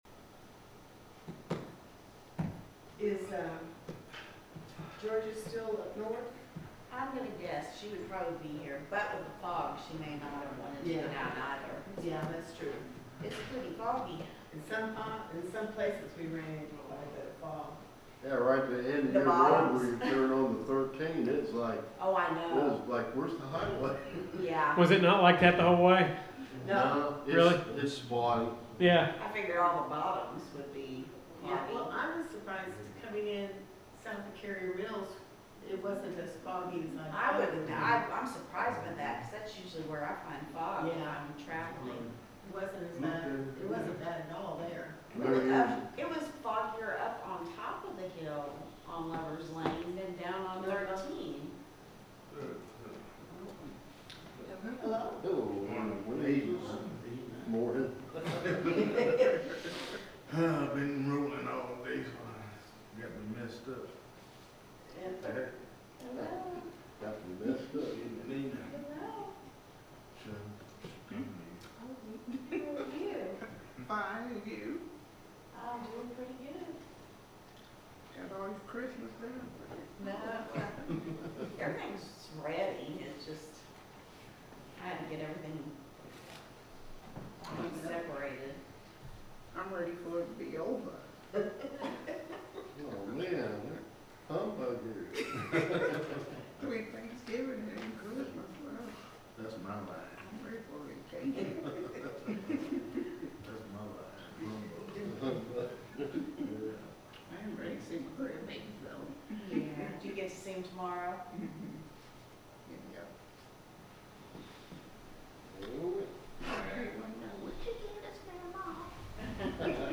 The sermon is from our live stream on 12/24/2025